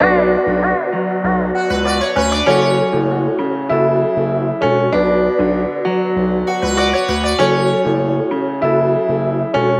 MELODY LOOPS
Bosco (195 BPM – Bm)
UNISON_MELODYLOOP_Bosco-195-BPM-Bm.mp3